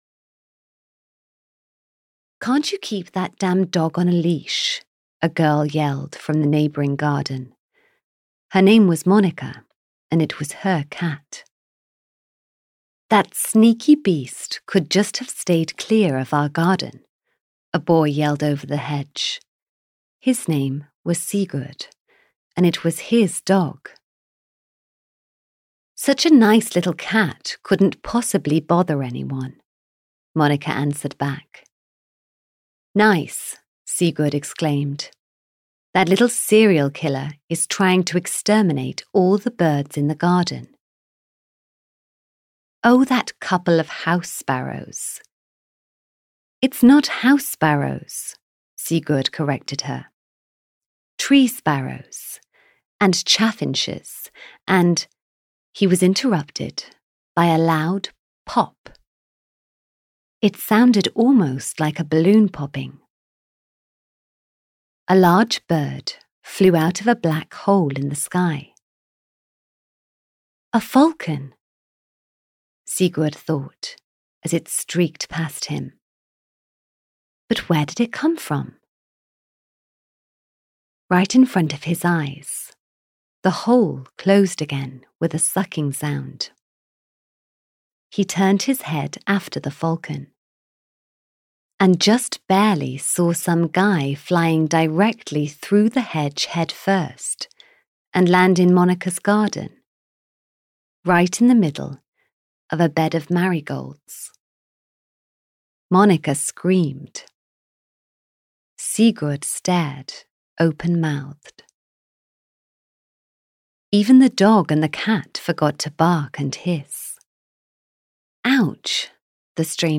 The Magical Falcon 1 - The Mad Sorceress (EN) audiokniha
Ukázka z knihy